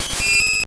Cri de Mimigal dans Pokémon Or et Argent.